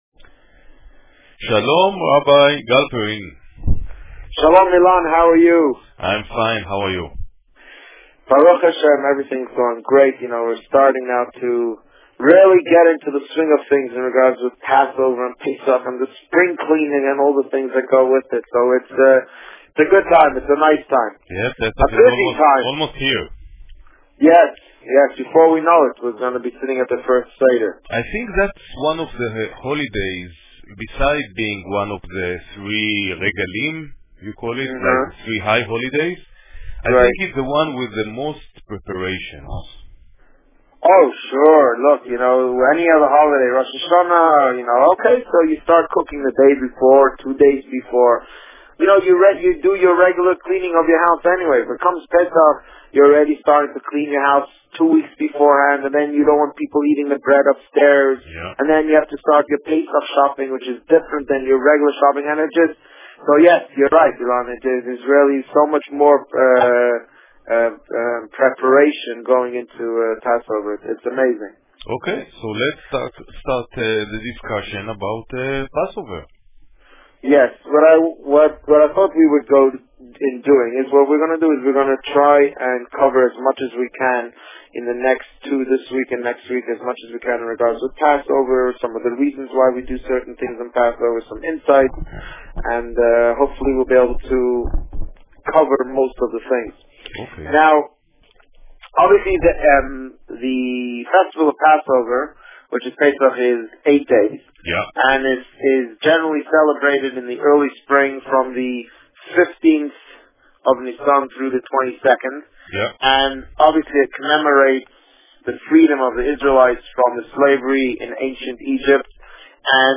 The Rabbi on Radio
Pesach - Part 1 Published: 07 April 2011 | Written by Administrator This week, the Rabbi speaks about the meaning of the Passover holiday and provides information about the two community seders. Click here for the interview.